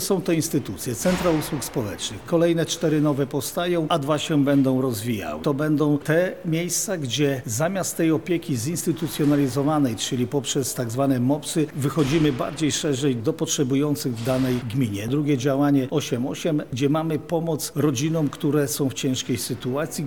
– mówi Jarosław Stawiarski, marszałek województwa lubelskiego.